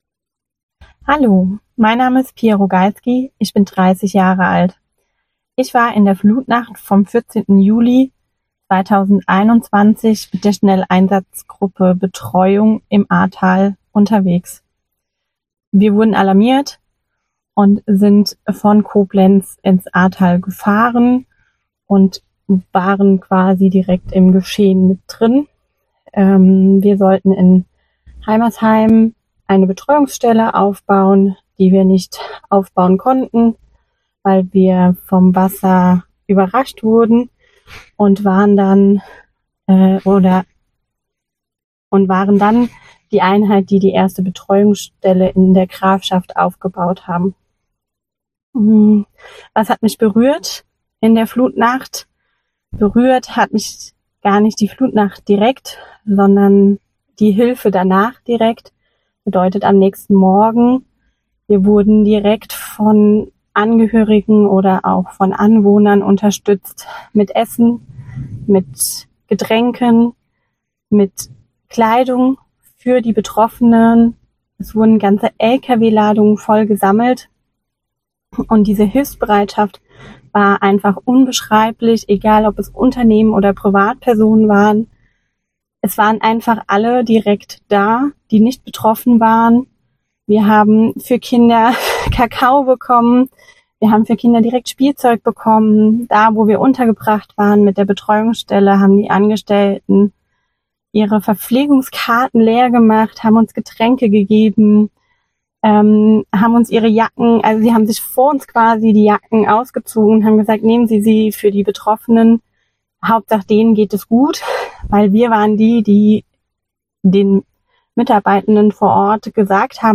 Sechs von ihnen teilen ihre persönlichen Eindrücke mit uns, sprechen ungefiltert und offen von ihren Erfahrungen, die sie bis heute begleiten.